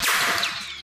SH GS SHOT.wav